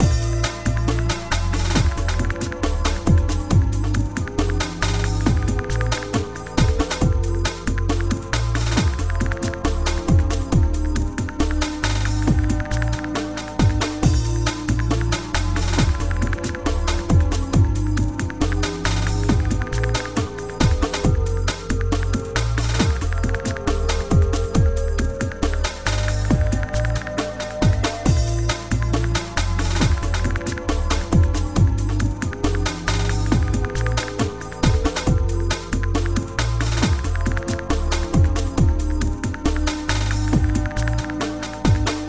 Jungle_loop.ogg